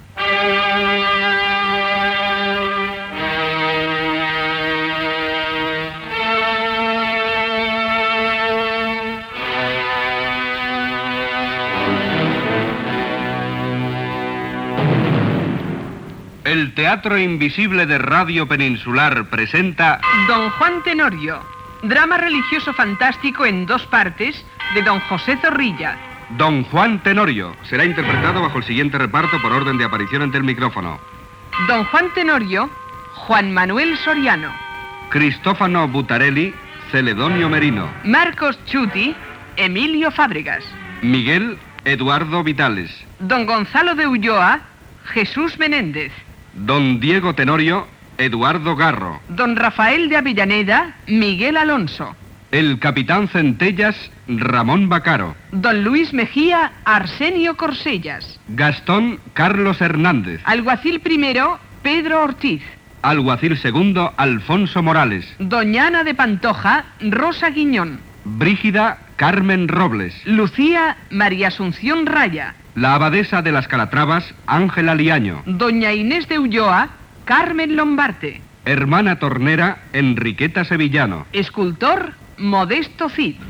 Careta d'entrada de "Don Juan Tenorio", de José Zorrilla, amb el repartiment.
Ficció